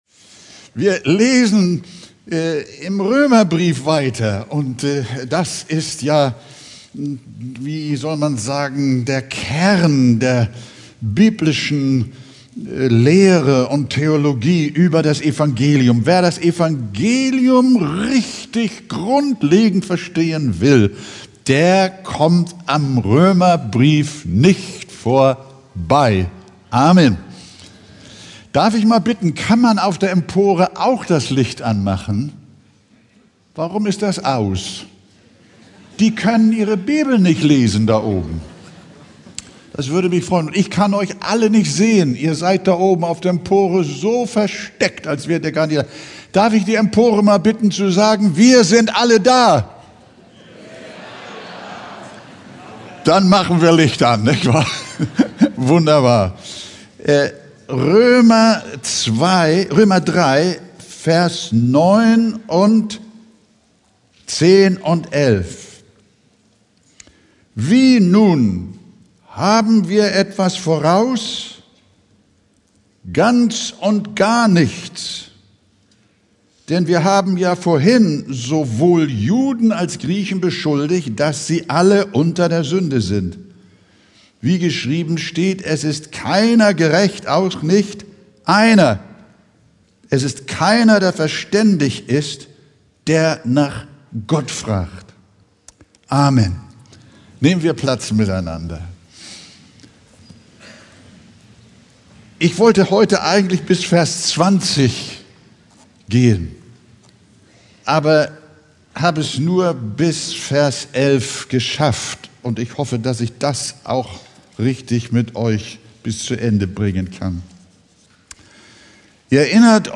Predigttext: Römer 3,9-11